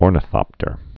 (ôrnə-thŏptər)